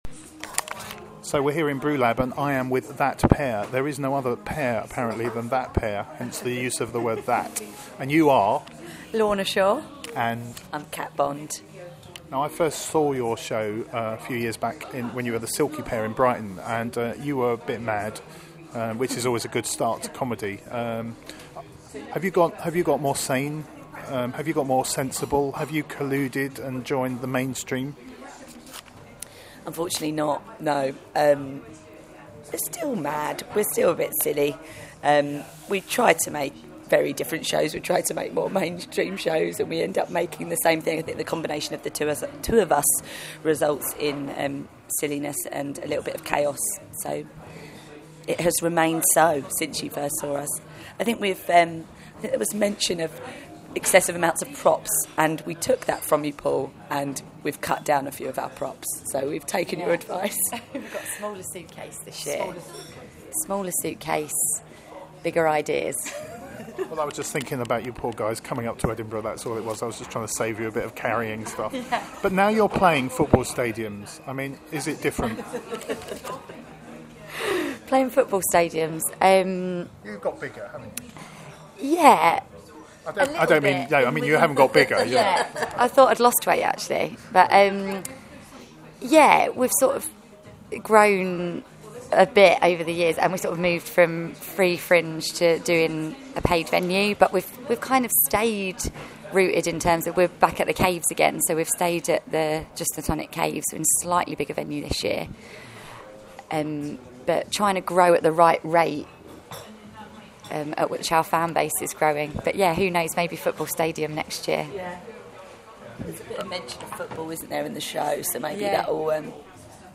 How do you find the right balance between doing what you love and need to do, and paying the rent? Listen to our interview